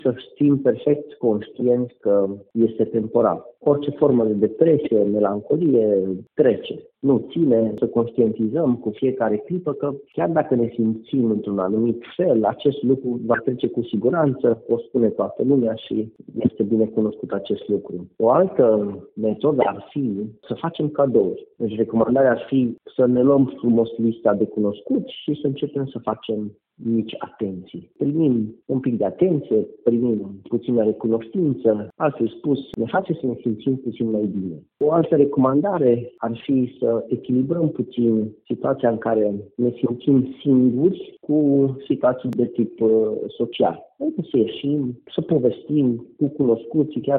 psihologul